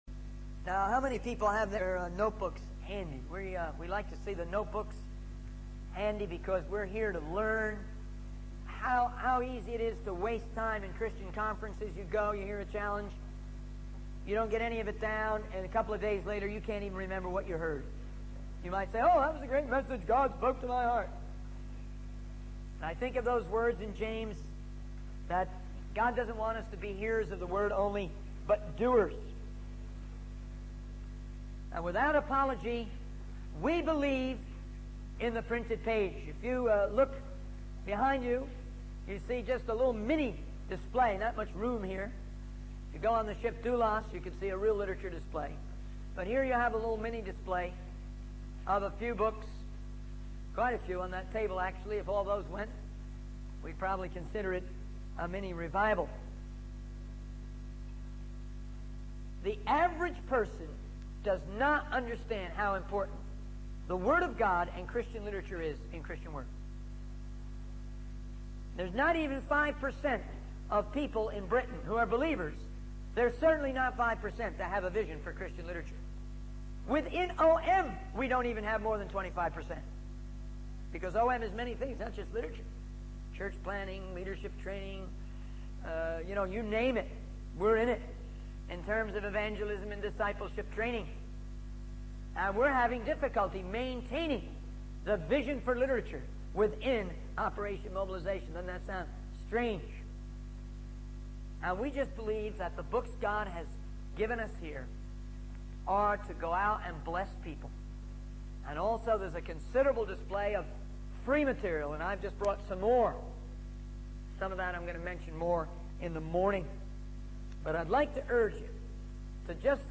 In this sermon, the speaker emphasizes the importance of taking notes during Christian conferences to avoid wasting time and forgetting the messages. He references James, highlighting the need to not just hear the word but also put it into practice.